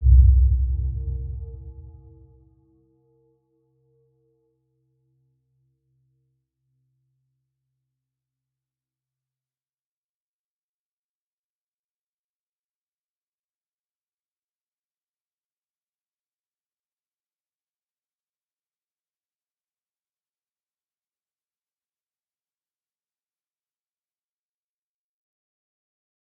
Round-Bell-C2-p.wav